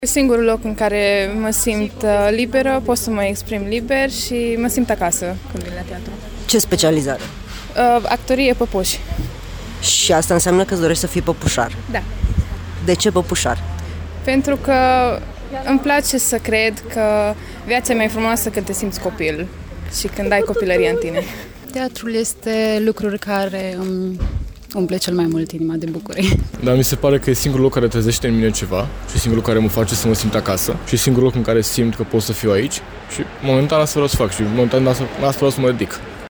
Proaspeţii studenţi spun că au ales această Universitate pentru că teatrul este ceea ce îi face să vibreze: